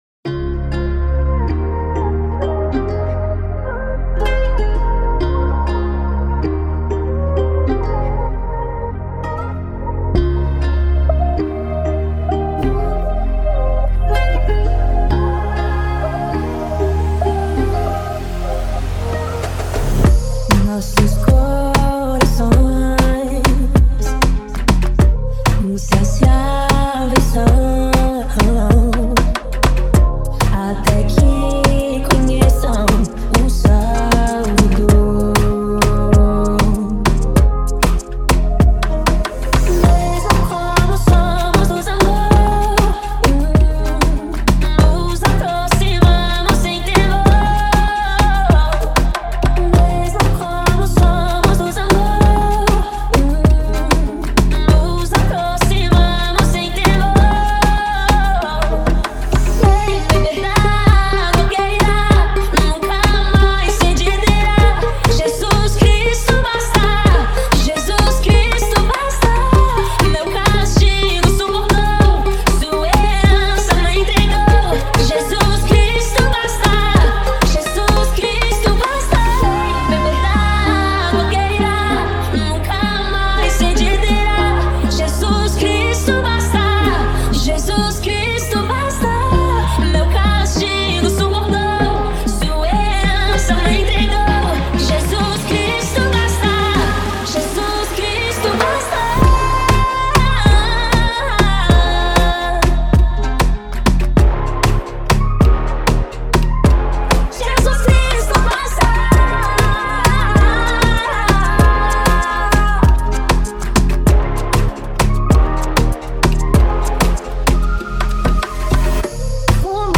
Gênero Gospel.